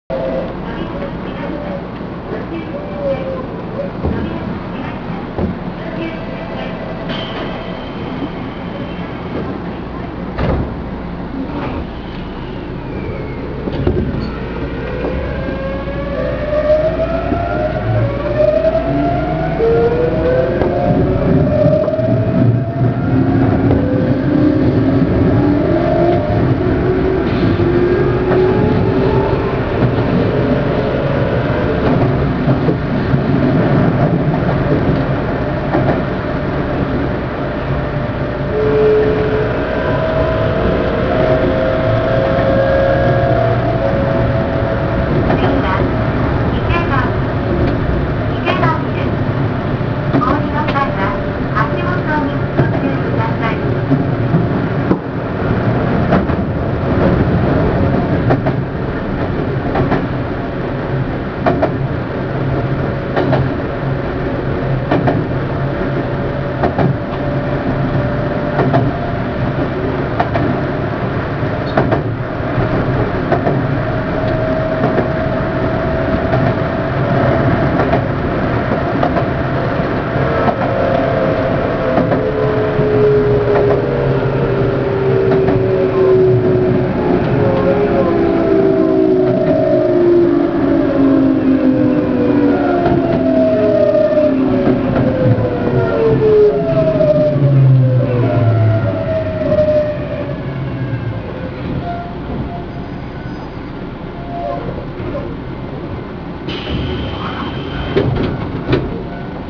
・7700系走行音
【池上線】千鳥町→池上（1分49秒：595KB）
見た目とは裏腹なVVVFのモーター音。一応、東洋GTOを採用しているのですが、良く聞く音とは随分と音の聞こえ方が異なります。